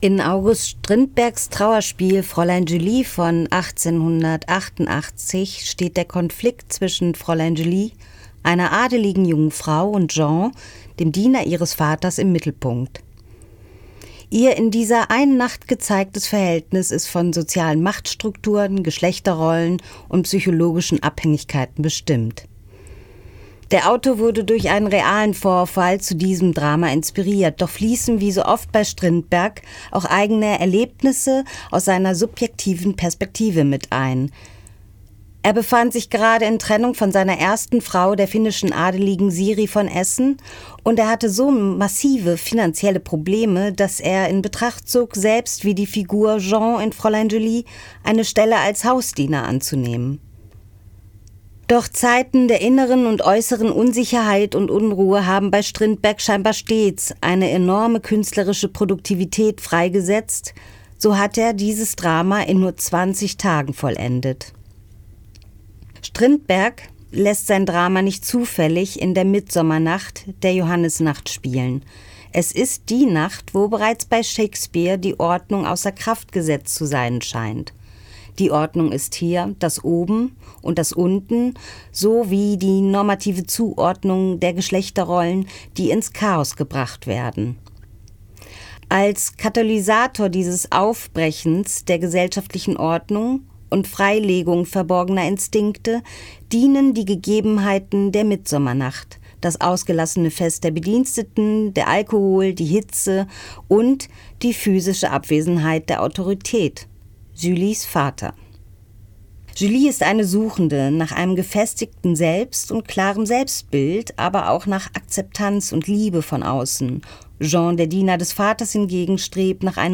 Höreinführung